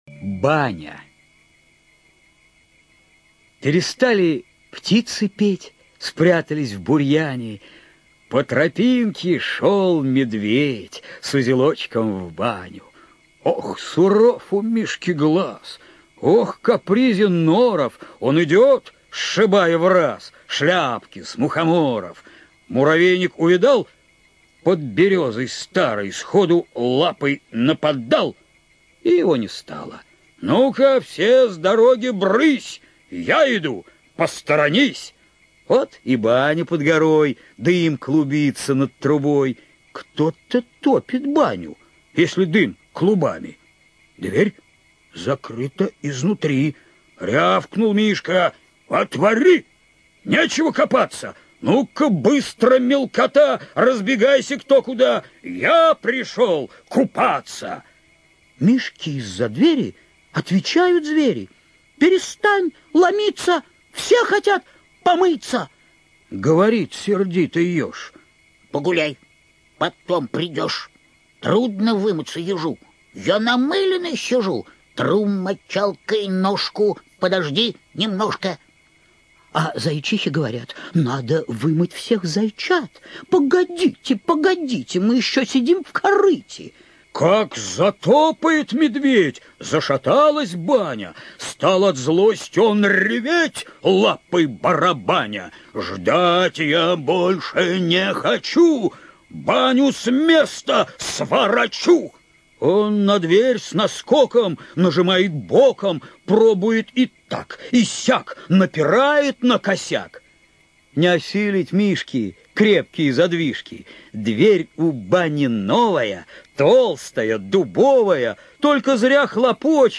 ЖанрДетская литература, Сказки, Поэзия